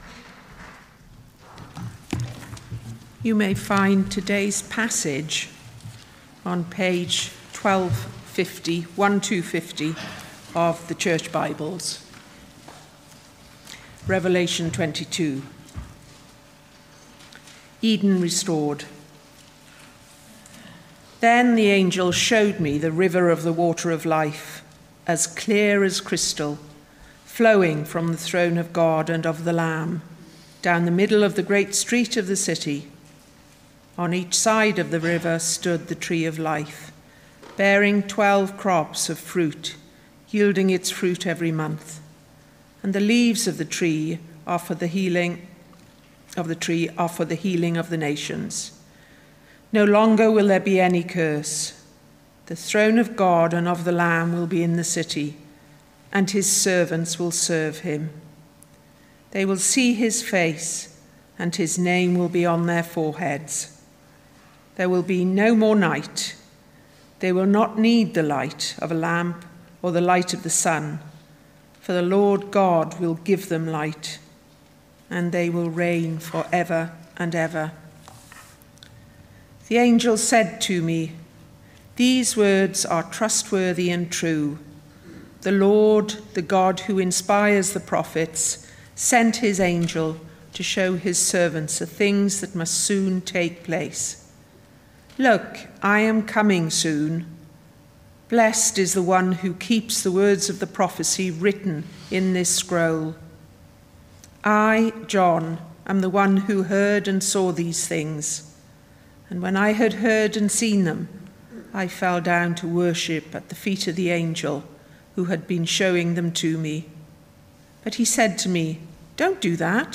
Revelation 22 Service Type: Sunday Morning Revelation 22